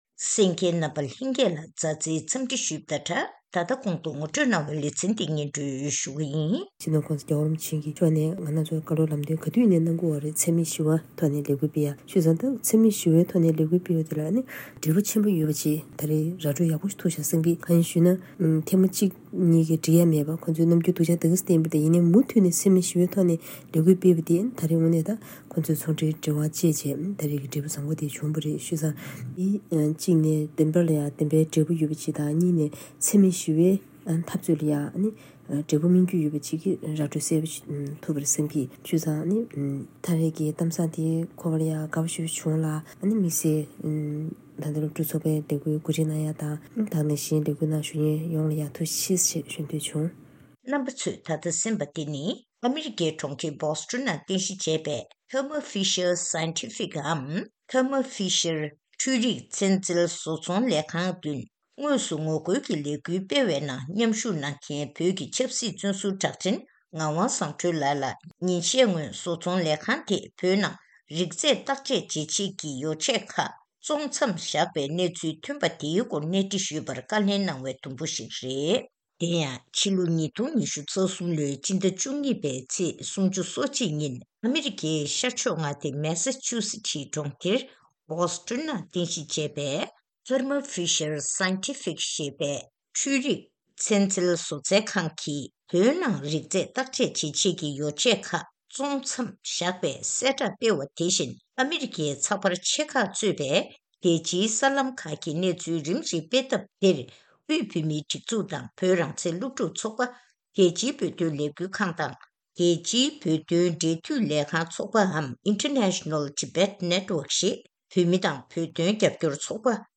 ཐེངས་འདིའི་གནས་འདྲིའི་ལེ་ཚན་ནང་།